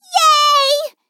Worms speechbanks
victory.wav